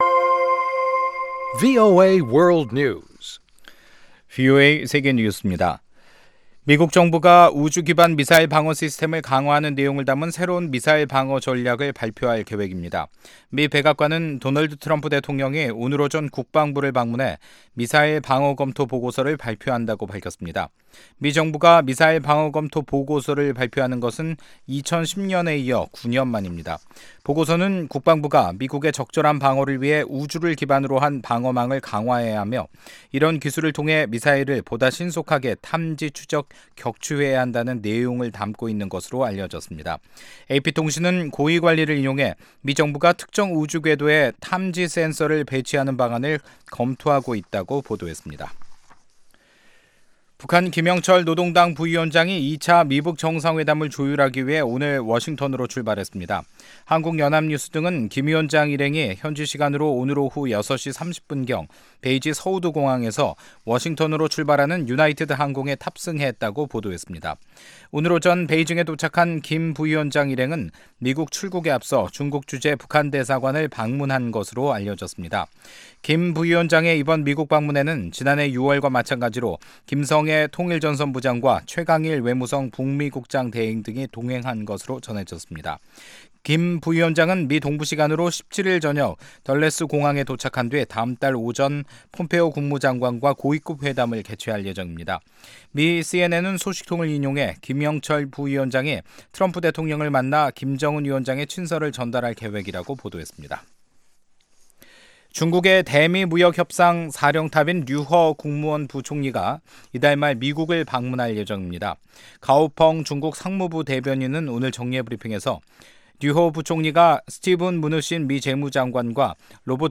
VOA 한국어 간판 뉴스 프로그램 '뉴스 투데이', 2019년 1월 17일 3부 방송입니다. 미 공화당 하원의원이 2차 미-북 정상회담 개최지로 베트남 하노이가 유력하다고 밝혔습니다. 마이크 펜스 부통령이 북한에 비핵화 조치를 밟을 것을 촉구했습니다.